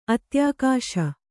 ♪ atyākāśa